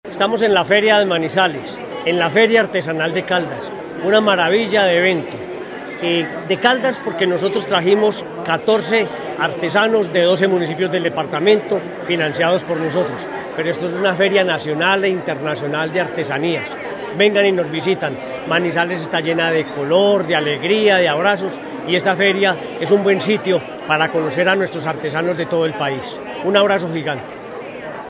Gobernador de Caldas recorrió la Feria Artesanal de Manizales, donde brillan las obras de los 14 artesanos caldenses apoyados en esta edición
Gobernador de Caldas, Henry Gutiérrez.